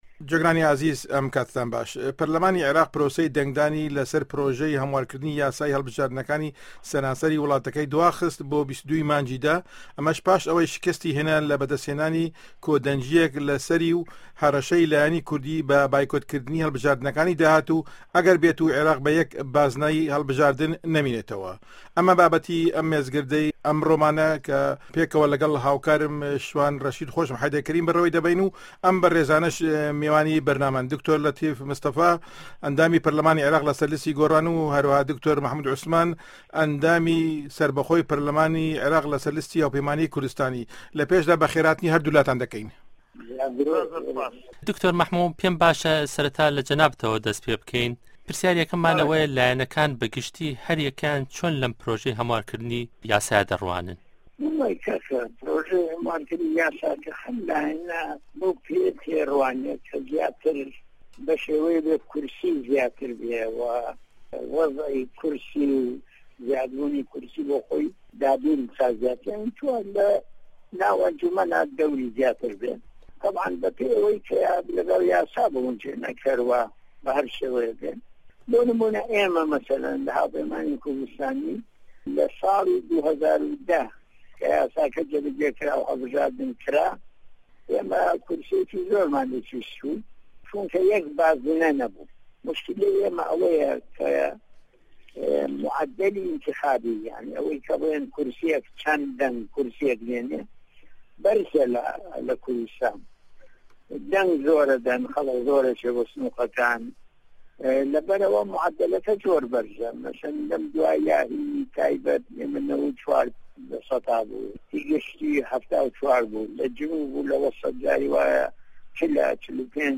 مێزگردێـک له‌ باره‌ی پـرۆژه‌ی هه‌موارکردنی یاسای هه‌ڵبژاردنه‌کانی عێراق